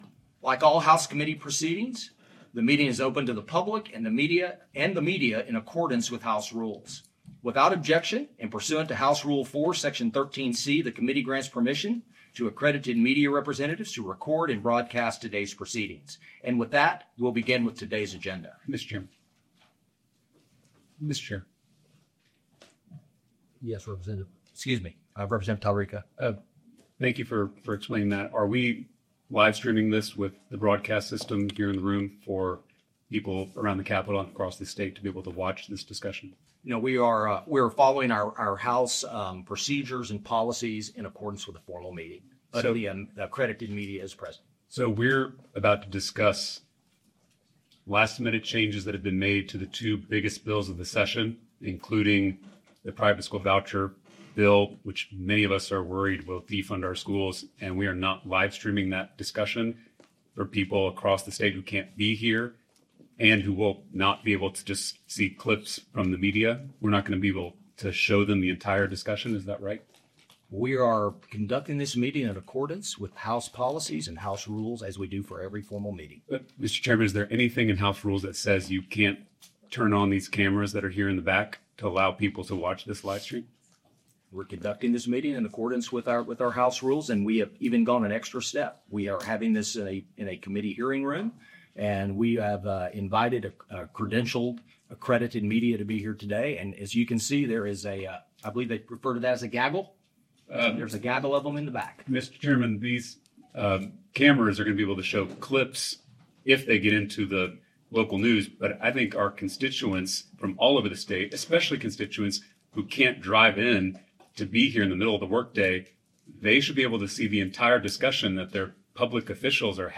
Normally, formal meetings are very short, but in this case the meeting lasted more than 2 ½ hours and featured contentious discussion of substantive changes in the committee substitutes that constituents would want to understand.
H-Pud-Ed-HB2-Meeting-Audio-EH2.mp3